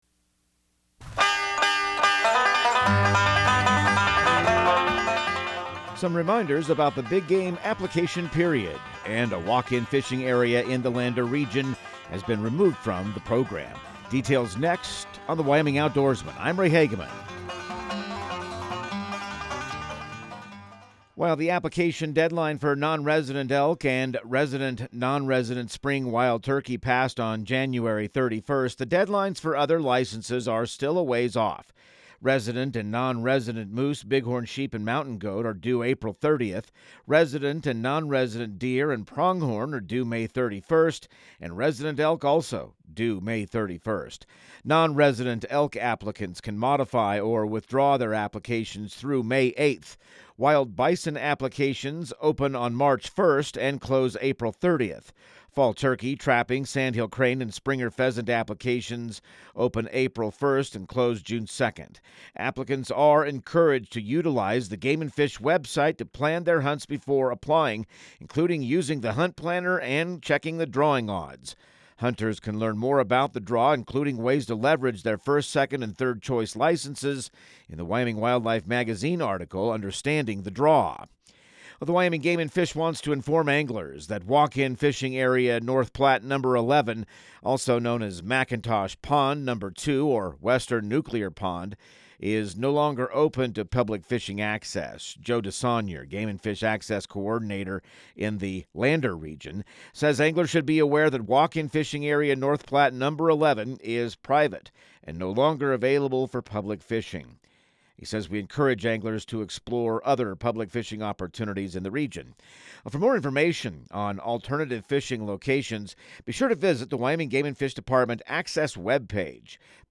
Radio news | Week of February 17